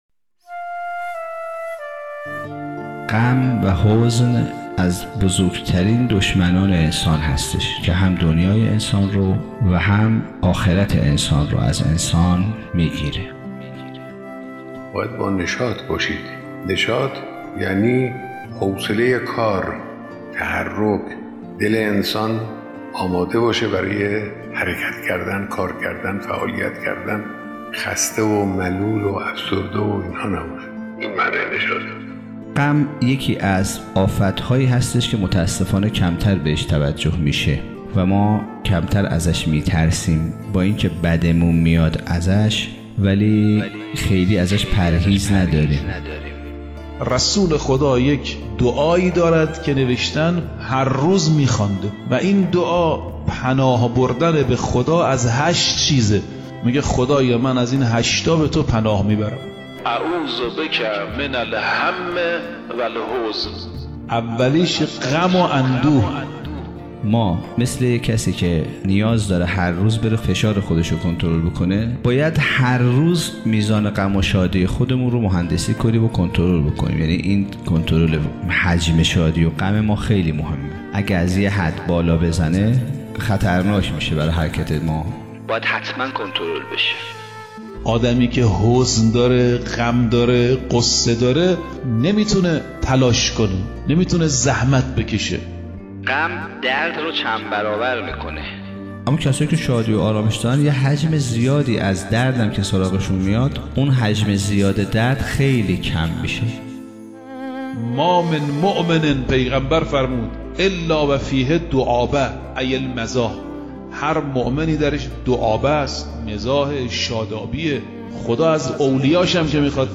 کارگاه تفکر